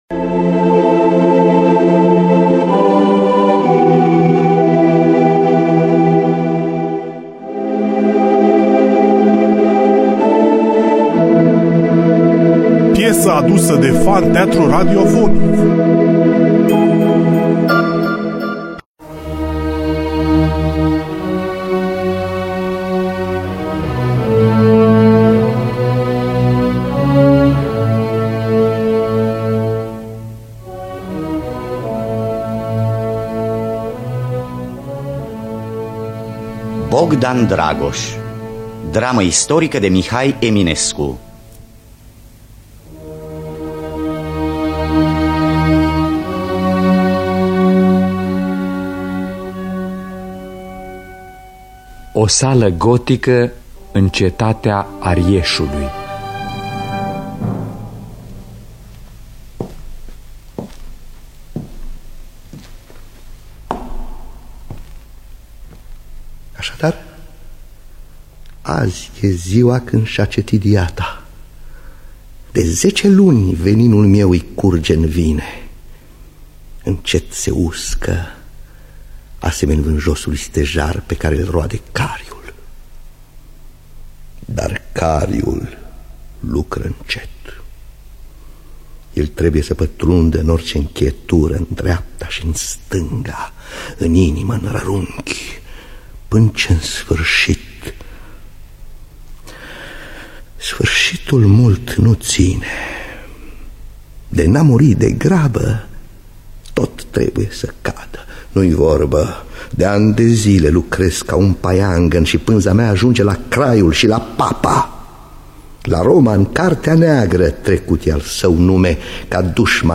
Bogdan Dragoş de Mihai Eminescu – Teatru Radiofonic Online